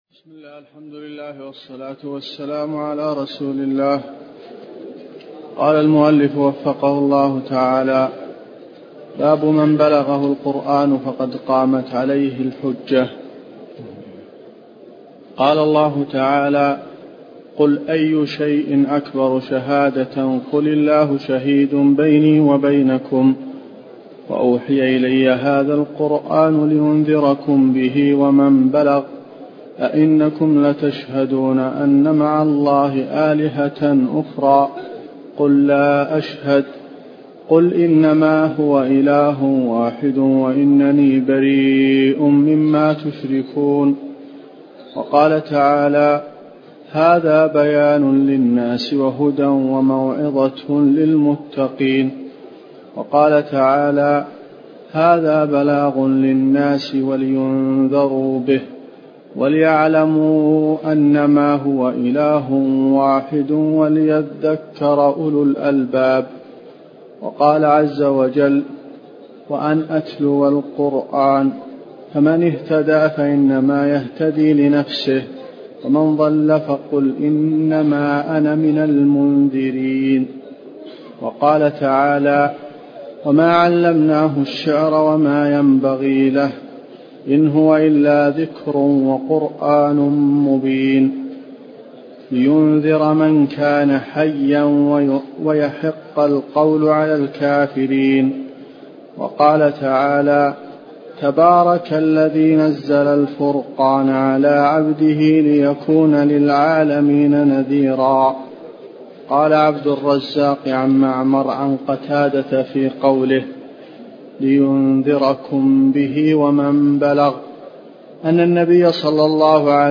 تفاصيل المادة عنوان المادة الدرس (12) شرح المنهج الصحيح تاريخ التحميل الأحد 15 يناير 2023 مـ حجم المادة 22.94 ميجا بايت عدد الزيارات 229 زيارة عدد مرات الحفظ 101 مرة إستماع المادة حفظ المادة اضف تعليقك أرسل لصديق